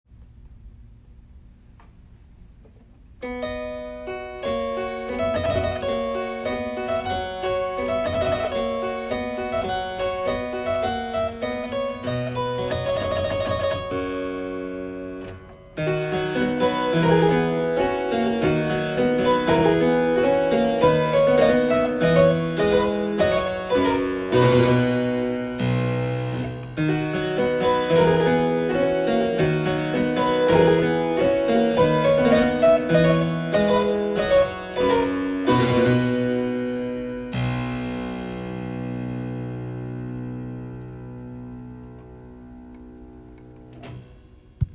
Double manual German harpsichord
Three choir 8'8'4' with buff stop, FF-g"' at 415 or 440 HZ....not transposing.
with a bold sound and firm action